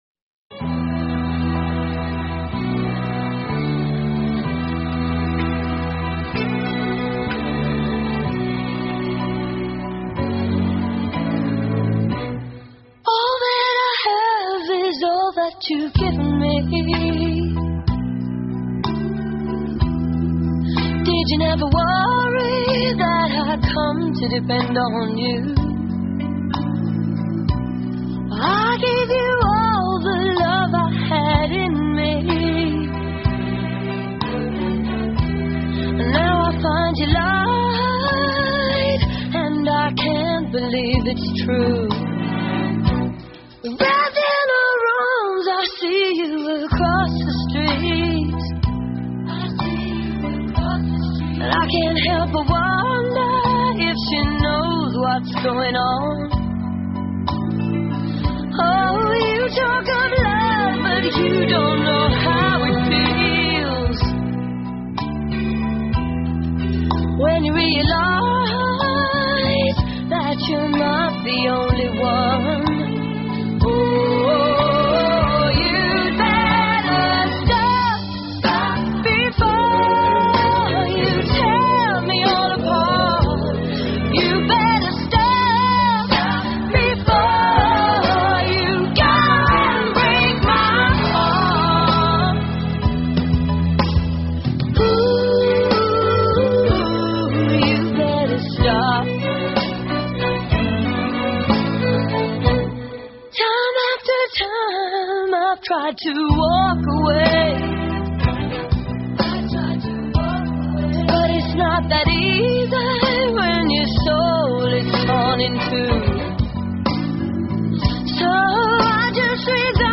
/32kbps) Описание: Старенькая,но очень душевная песня.